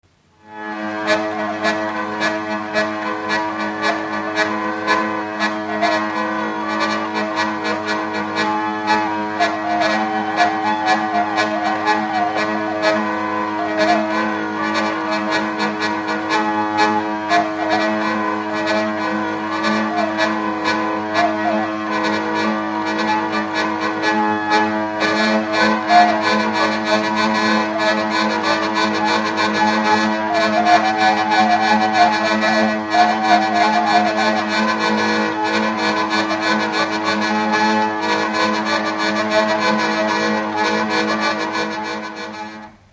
Dallampélda: Hangszeres felvétel
Alföld - Csongrád vm. - Fábiánsebestyén
tekerő Műfaj: Oláhos Gyűjtő